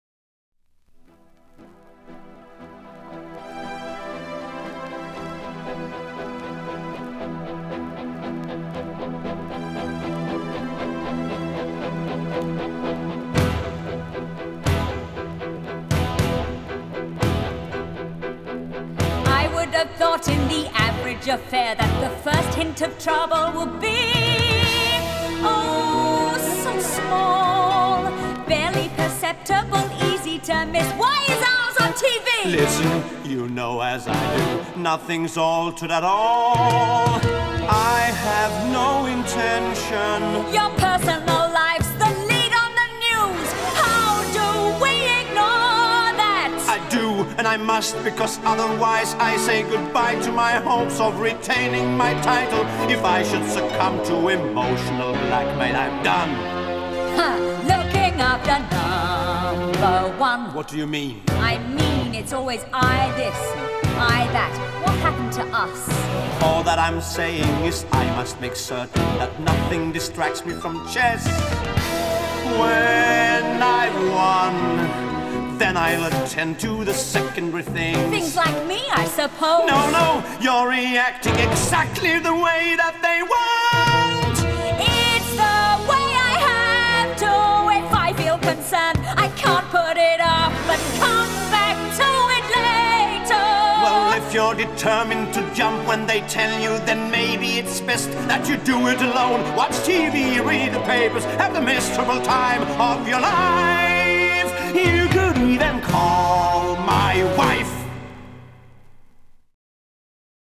Жанр: Electronic, Rock, Pop, Stage & Screen
Стиль: Pop Rock, Musical, Ballad, Synth-pop